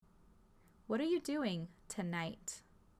Вот так говорят данные слова сами носители языка, ударение на последний слог:
• Вечером — tonight — [təˈnaɪt] — tэ night;
Прослушайте живое произношение с примерами этих слов в предложениях.
Произношение — tonight